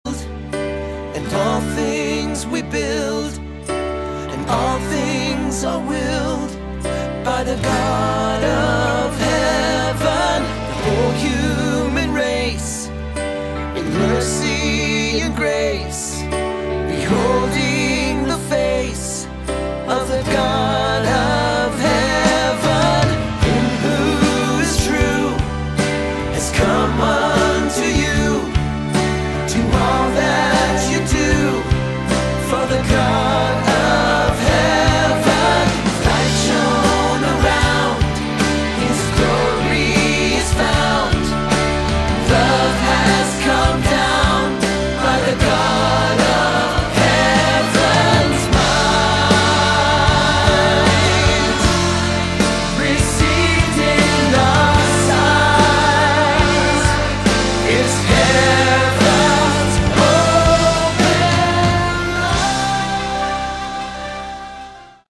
Category: Prog / AOR
keyboards, guitar
bass
drums